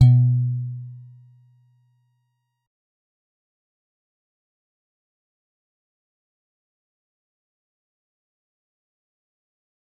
G_Musicbox-B2-mf.wav